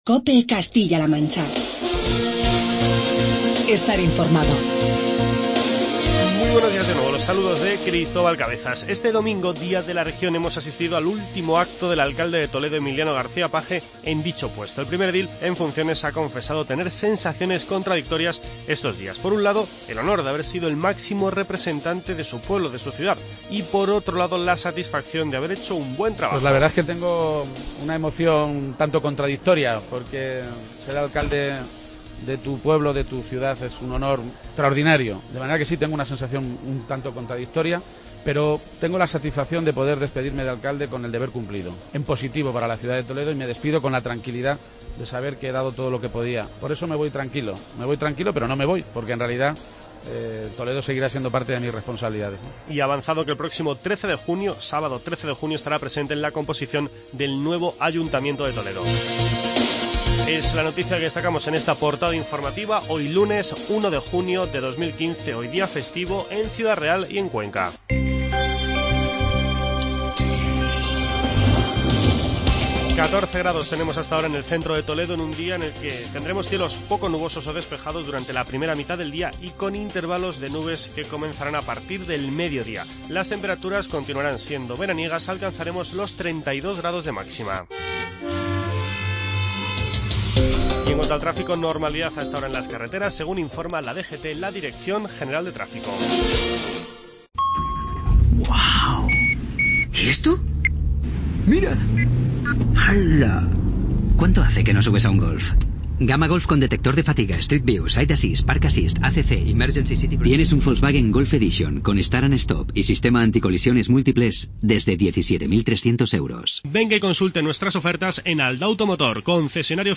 Informativo provincial
Escuchamos las palabras del alcalde en funciones de Toledo, Emiliano García-Page, con motivo del Día de la Región